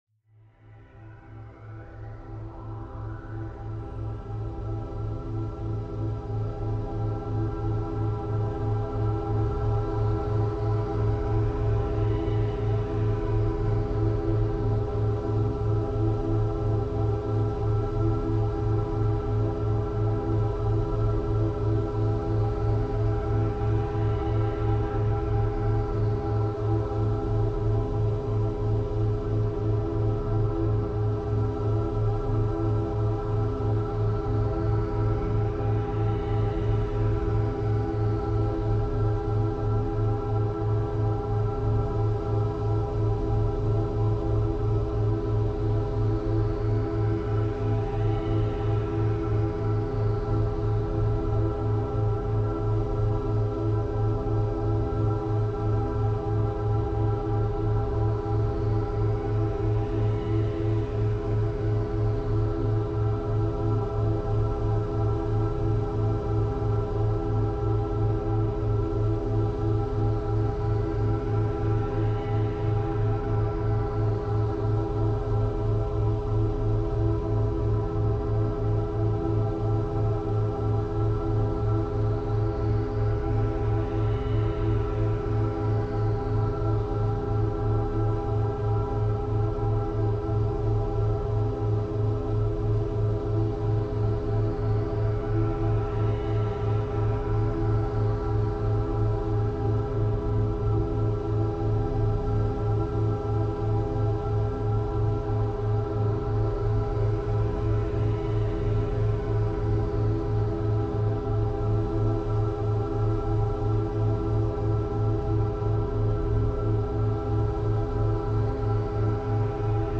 リラックス音楽が、緊張をやわらげ、体の隅々まで安らぎを届ける。
勉強BGM